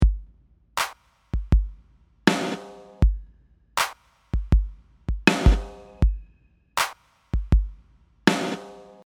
Gated reverb, side-chained to the snare:
gatesidechain.mp3